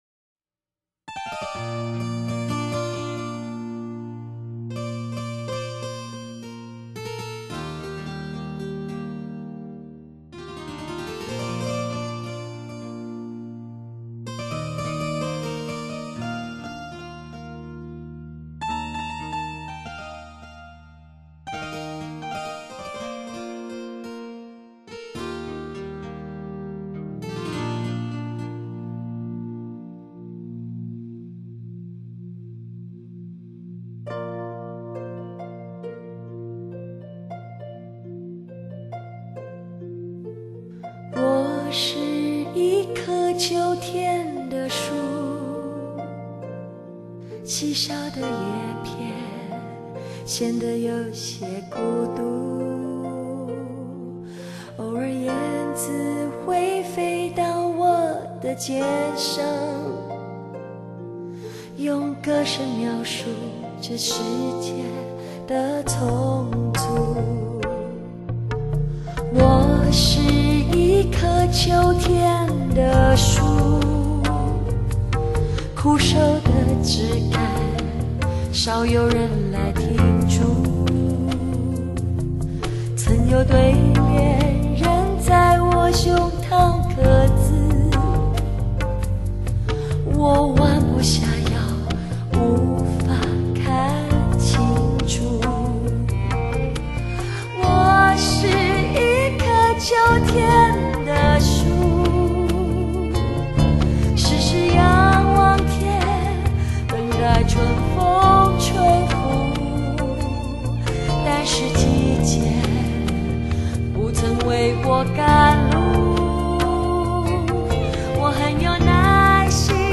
她的嗓音略带沙哑，充满了感性。
曲风风格迥异，配器时尚新颖。高品质lpcd后期制作，令音响效果更加出彩！